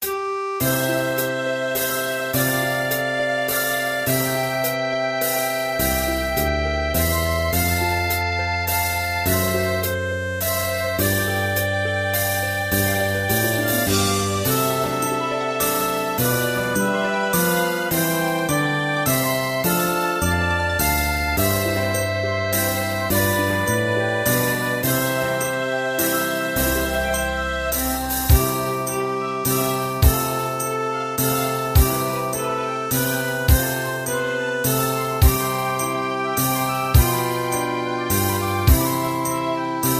大正琴の「楽譜、練習用の音」データのセットをダウンロードで『すぐに』お届け！